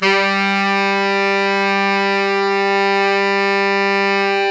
SAX_sfg3x    228.wav